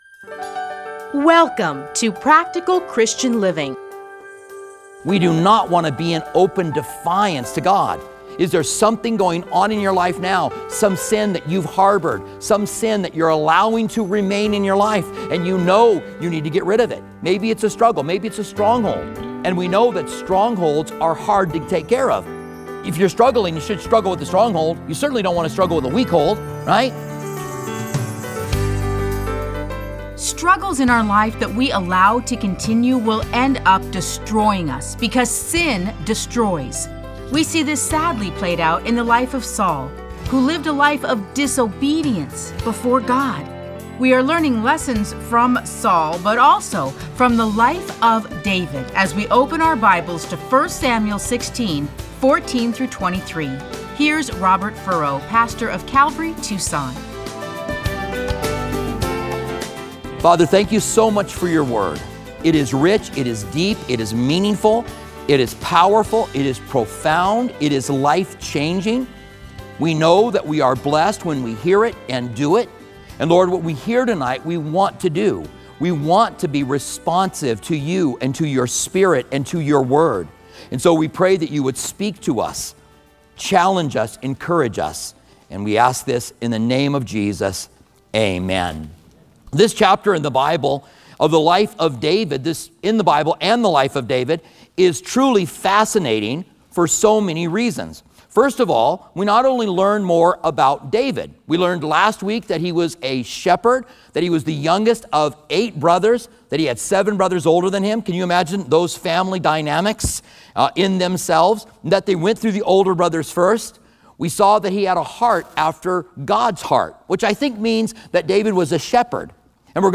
Listen to a teaching from 1 Samuel 16:14-23.